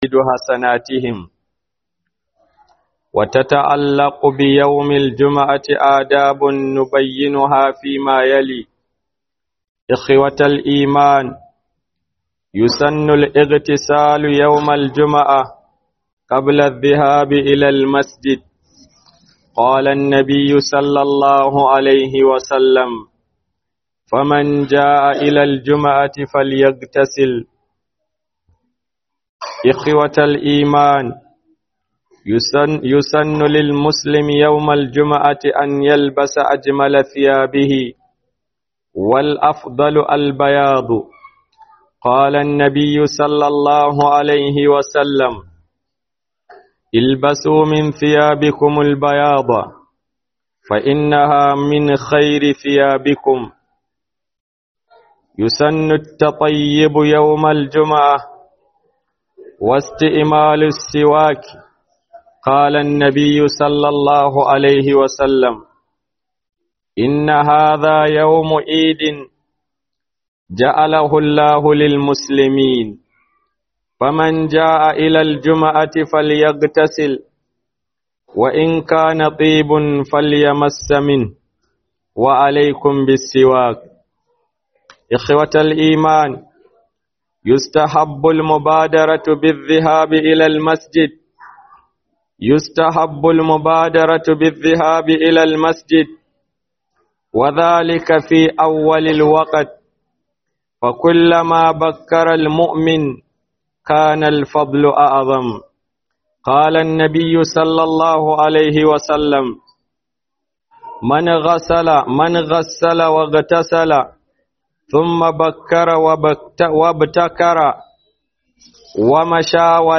Huduba - Falalar Ranar Jumaa